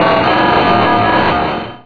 Cri de Métalosse dans Pokémon Rubis et Saphir.